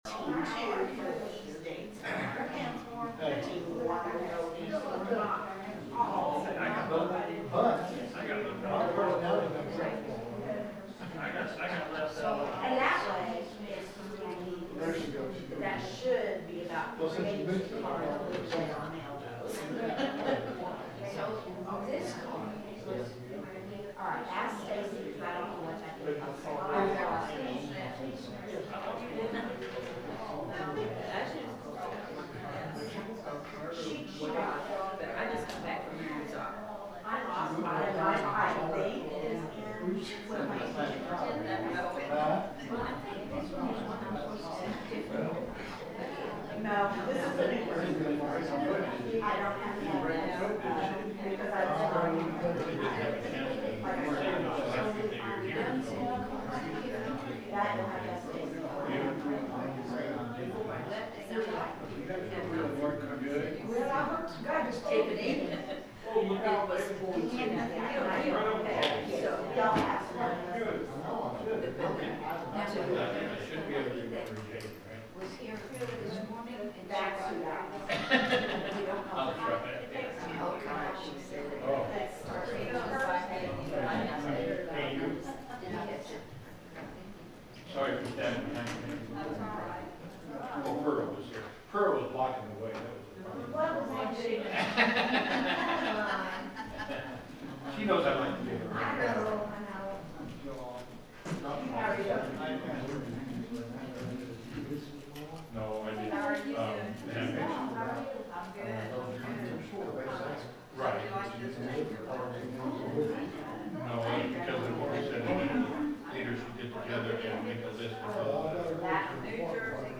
The sermon is from our live stream on 2/22/2026